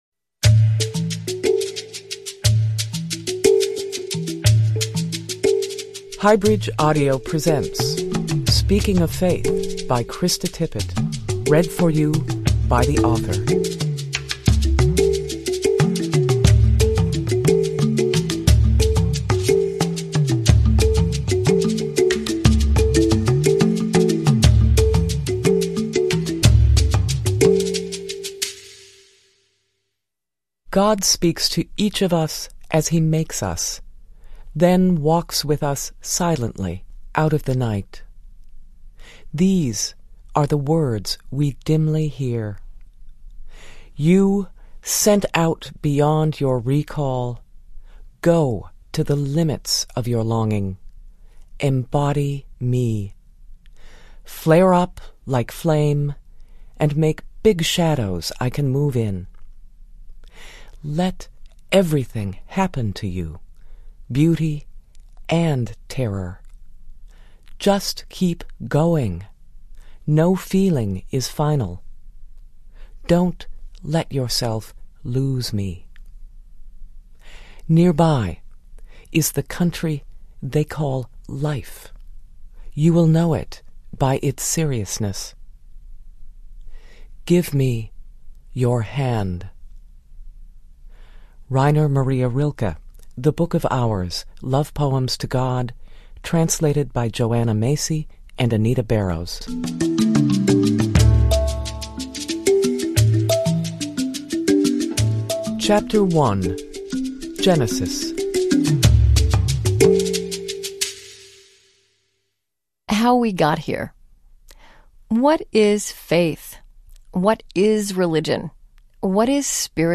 The host of the popular weekly program on National Public Radio talks about her journey from politics back to religion-and a life of conversation. Includes excerpts from radio program.